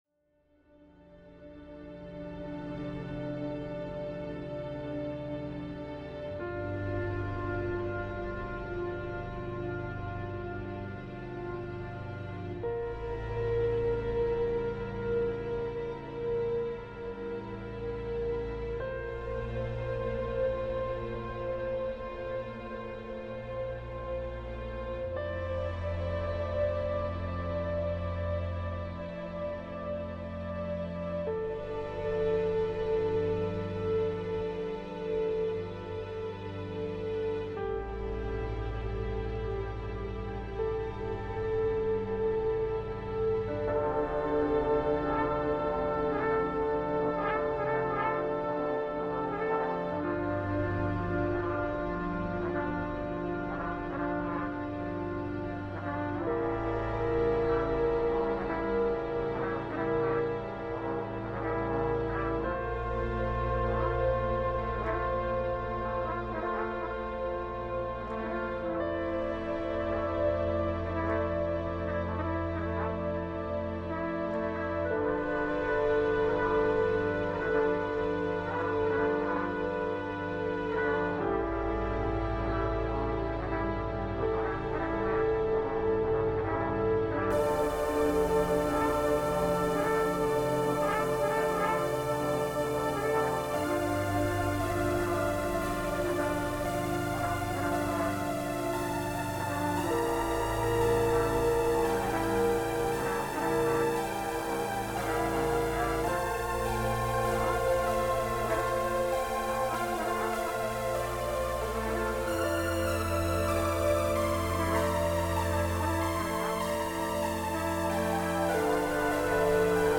Trumpets and synths collide.
Experimental 3:05 Film Score